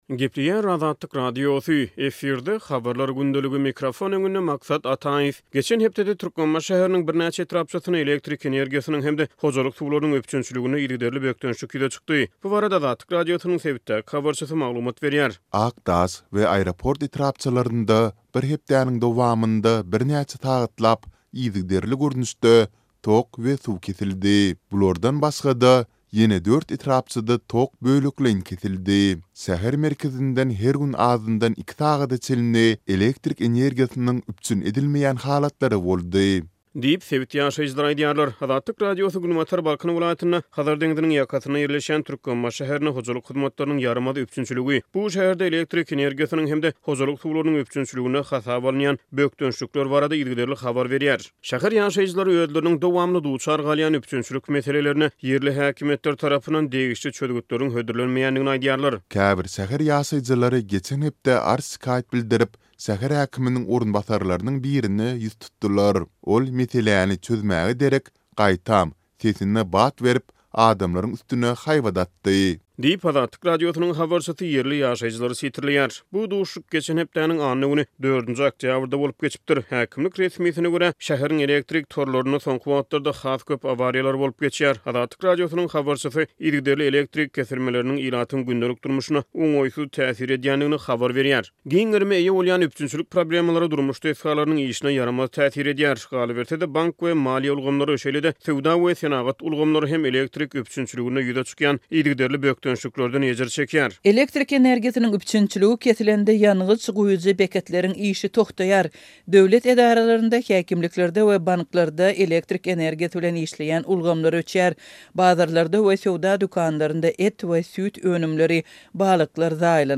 Geçen hepdede Türkmenbaşy şäheriniň birnäçe etrapçasynda elektrik energiýasynyň hem-de hojalyk suwlarynyň üpjünçiliginde yzygiderli bökdençlik ýüze çykdy, häkimiýetler dessin çözgüt hödürläp bilmedi. Bu barada Azatlyk Radiosynyň habarçysy sebitden maglumat berýär.